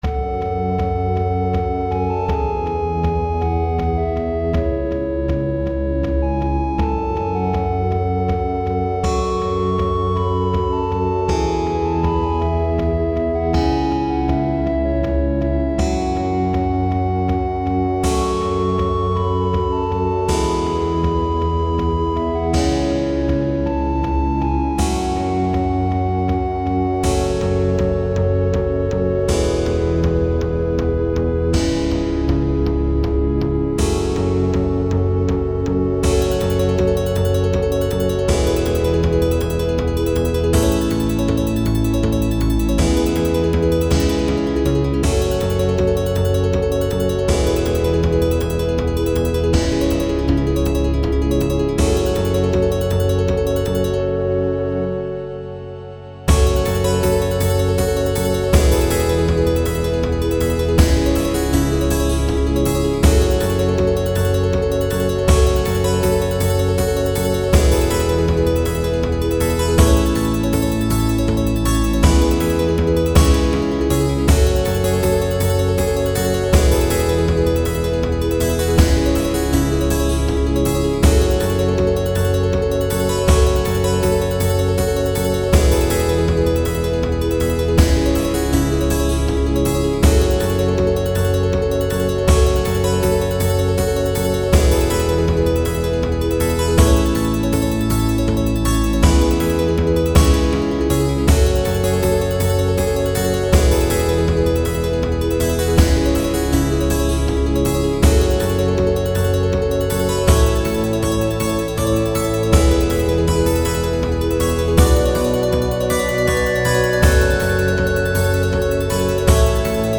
5:00 – Medieval, electronic instruments.